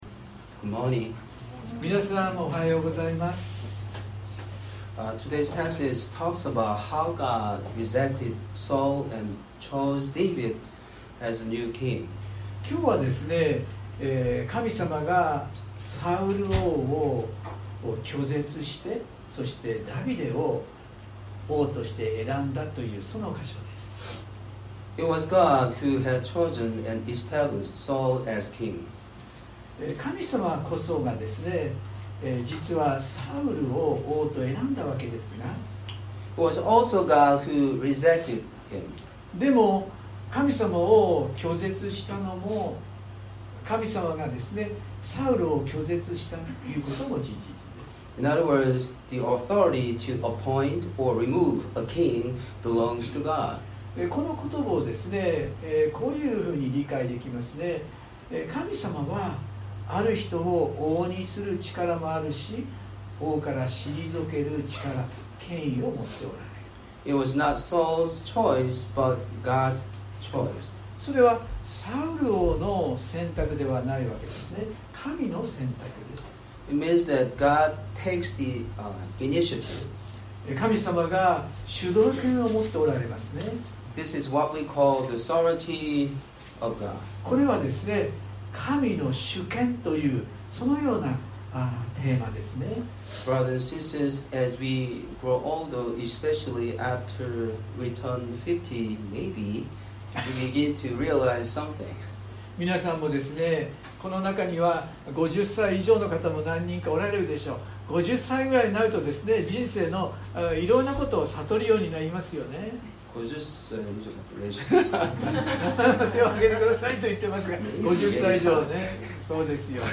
↓Audio link to the sermon:(Sunday worship recording) (If you can’t listen on your iPhone, please update your iOS) Today’s passage talks about how God rejected Saul and chose David as the new king.